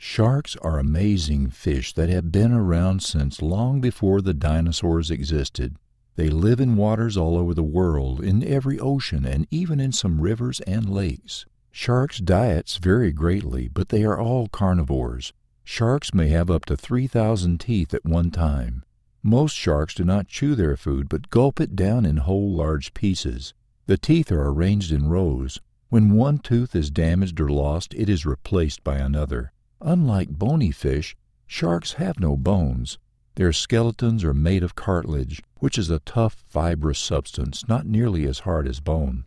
Male
English (North American)
Adult (30-50), Older Sound (50+)
Smooth, trusting, deep, believeable, friendly,
authoritative, corporate, professional, sexy,
kind, loving, fatherly, grandfatherly, military,
Studio Quality Sample